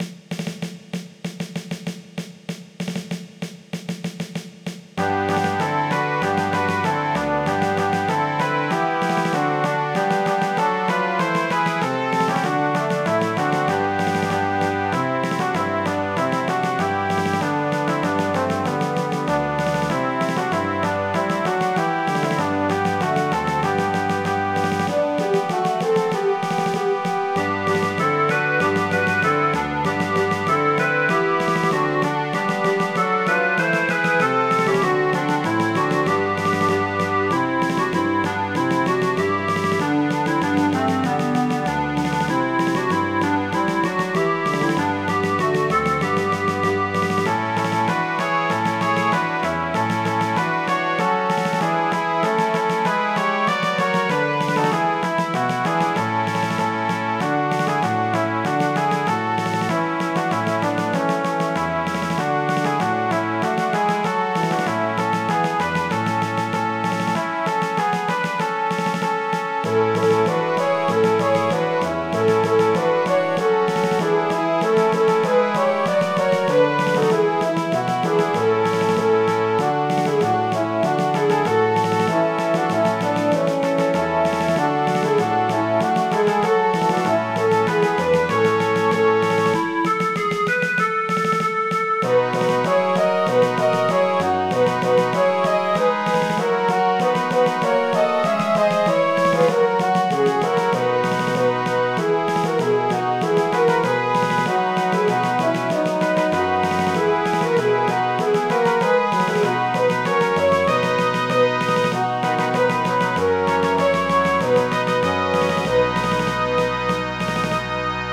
Midi File, Lyrics and Information to Yankee Doodle